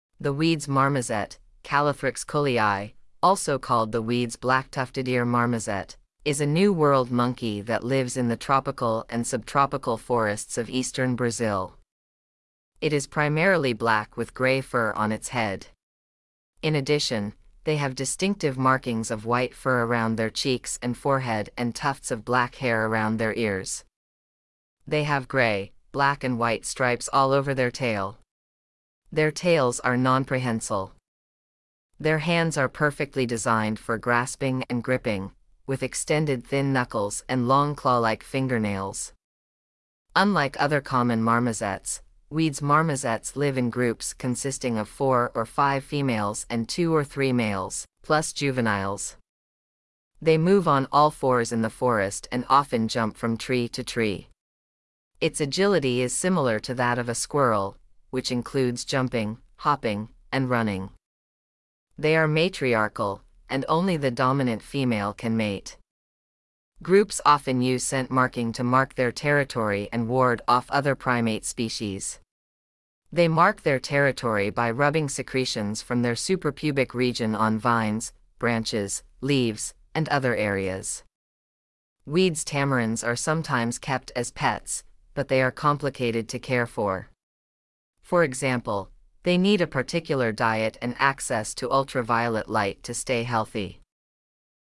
Wied's Marmoset
Wieds-Marmoset.mp3